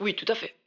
VO_ALL_Interjection_17.ogg